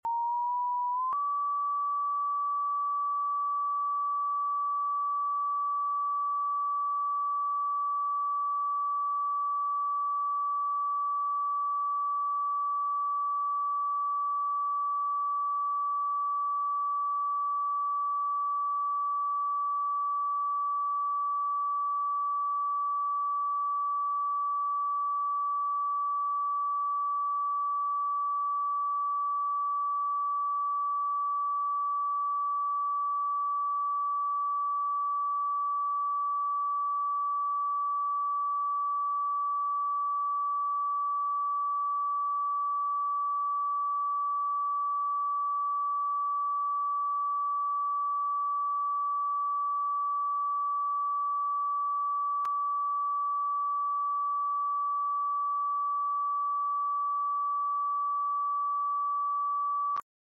1150 Hz: Can You Feel sound effects free download